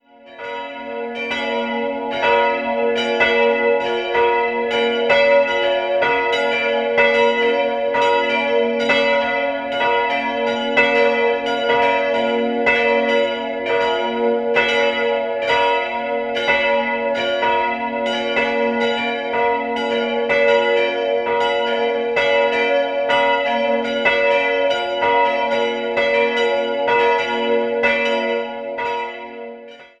3-stimmiges Geläute: b'-des''-f'' Die große Glocke stammt noch aus dem ersten Geläut und wurde 1932 von Hahn&Sohn (Landshut-Reichenhall) gegossen.